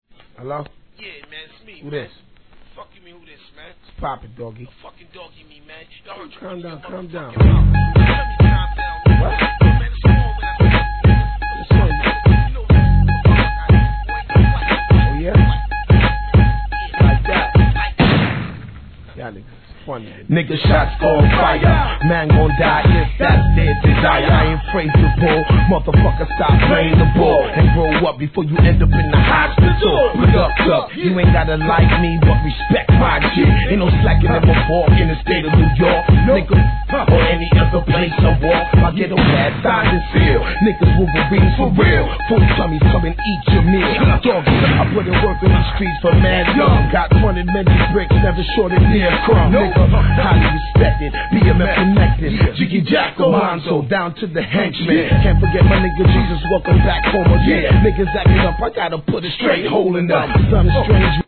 HIP HOP/R&B
電子音が効いた印象的なBEATでフロア栄え抜群でしょう!!